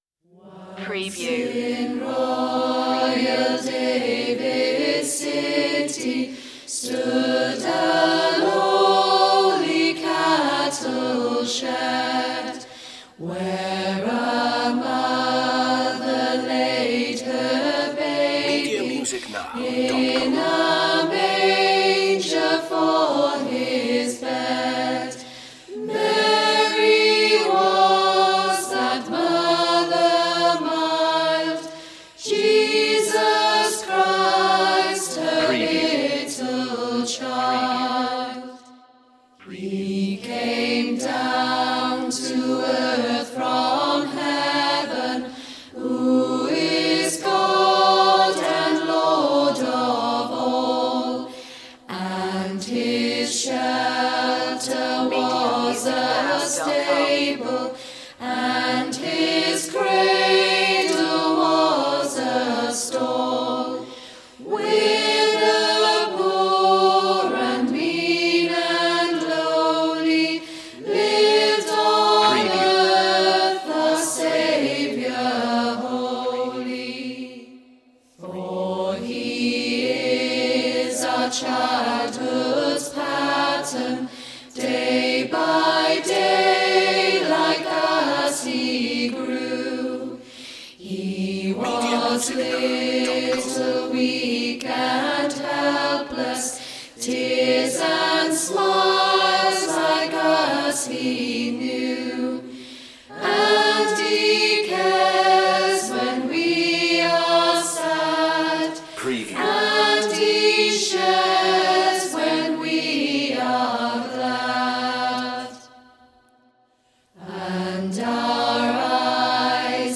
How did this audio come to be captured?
100% Live in studio re...